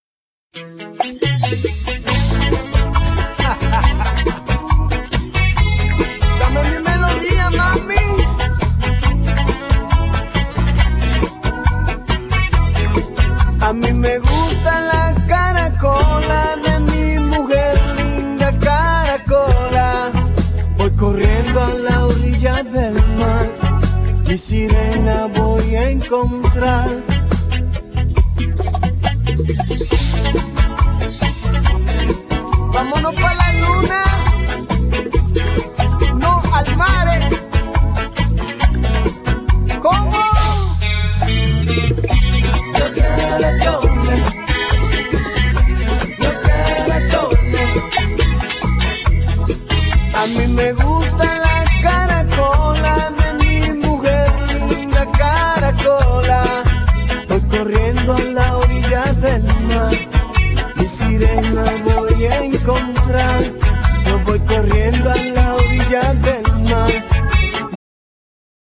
Genere : Pop folk latino